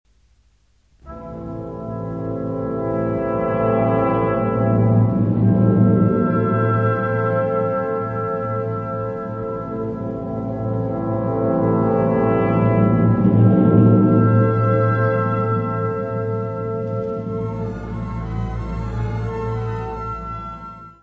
Peer Gynet, incidental music
: stereo; 12 cm + folheto
Área:  Música Clássica